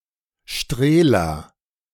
Strehla (German pronunciation: [ˈʃtʁeːla]
De-Strehla.ogg.mp3